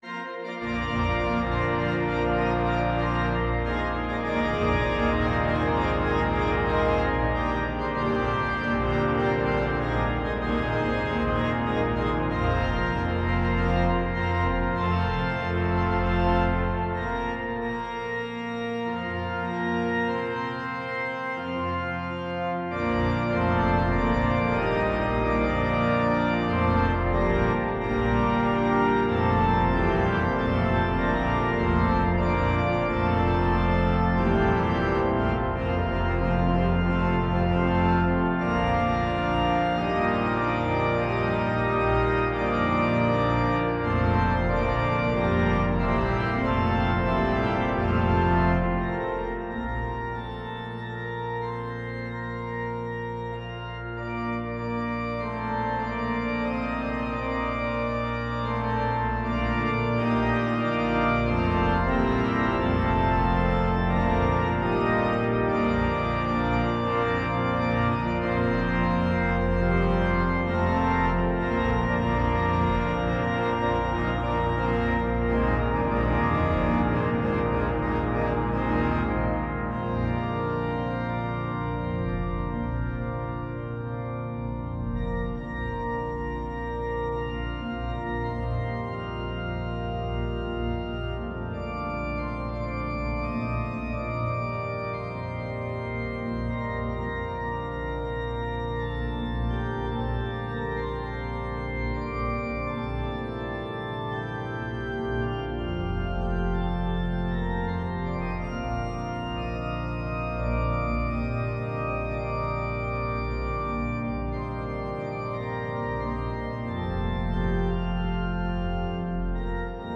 Voicing: Organ